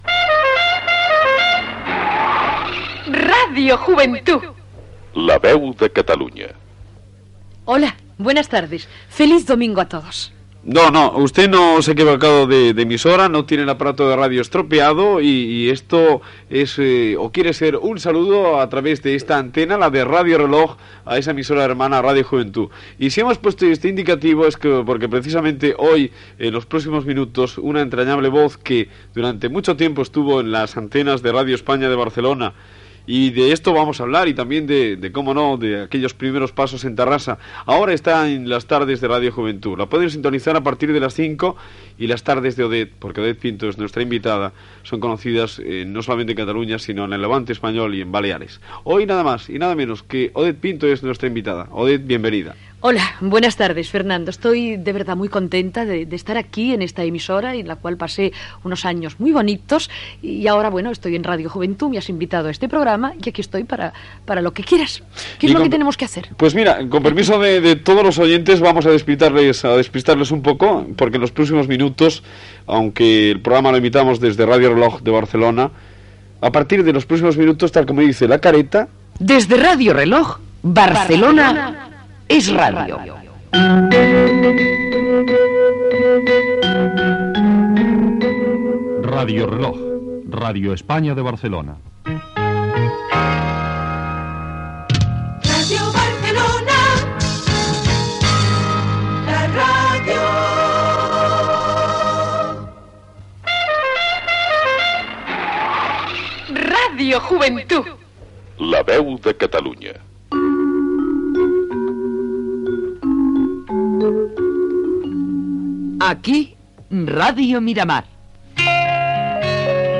Inclou els indicatius de les emissores de Barcelona de l'any 1982.
Entreteniment